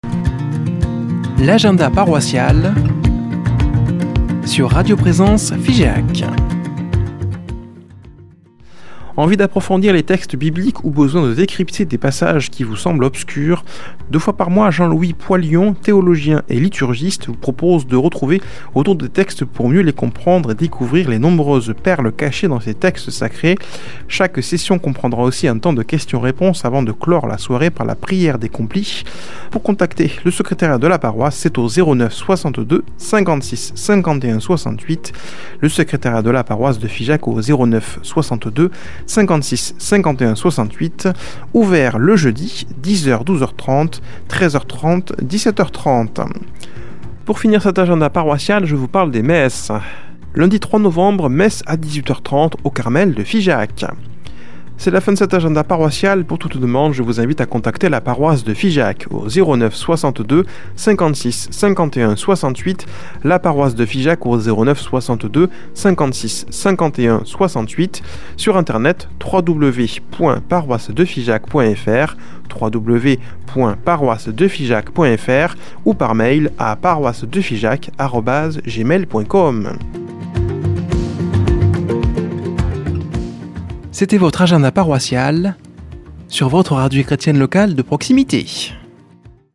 Une émission présentée par
Présentatrices